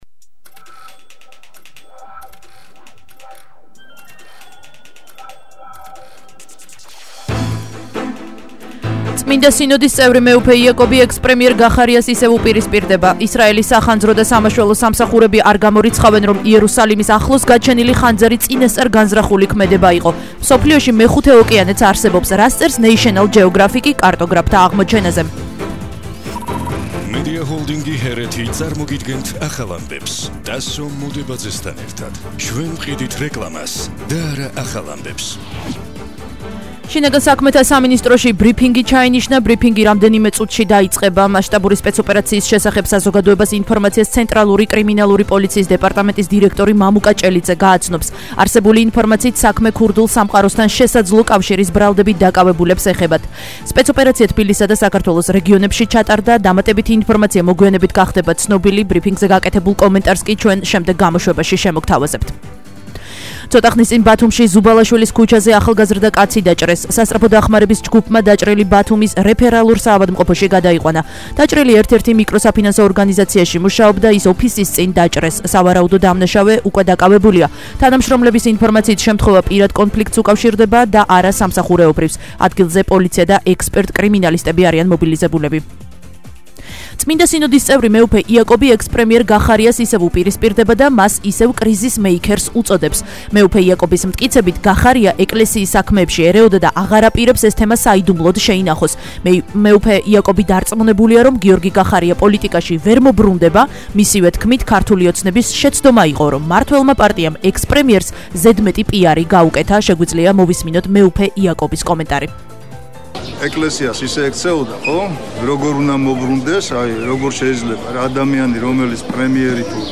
ახალი ამბები 14:00 საათზე –10/06/21